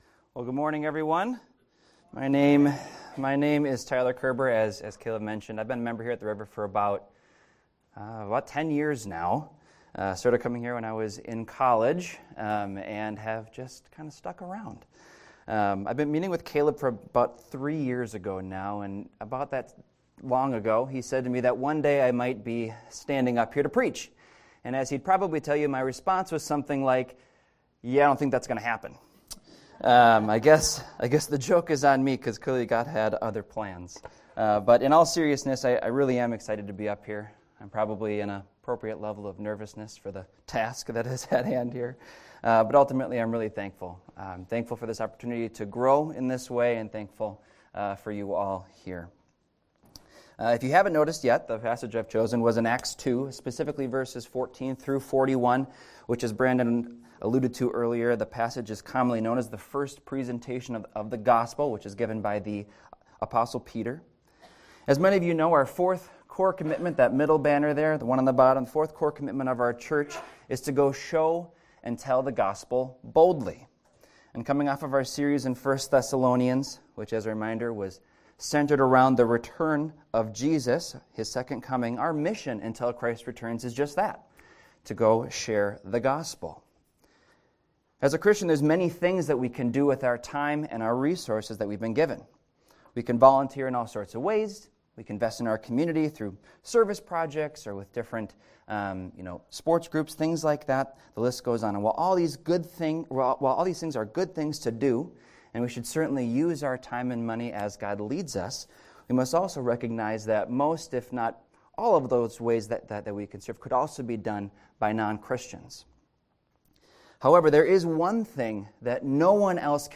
This is a recording of a sermon titled, "Boldness in the Spirit."